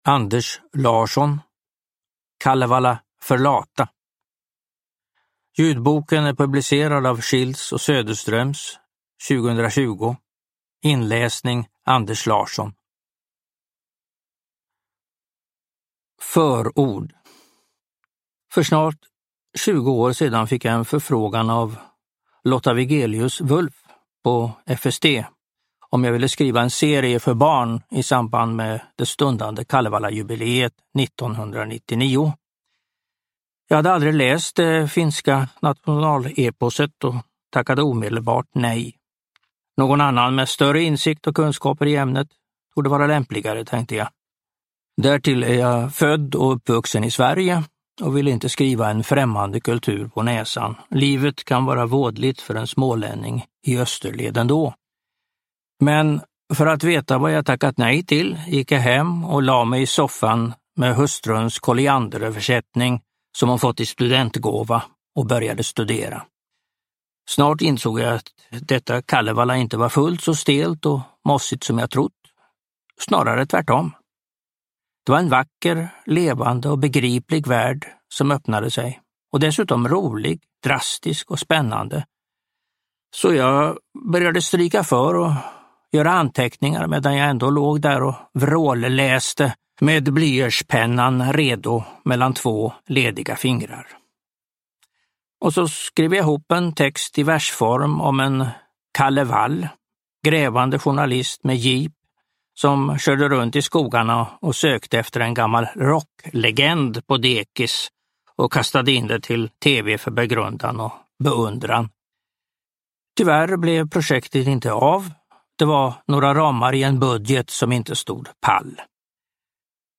Kalevala för lata – Ljudbok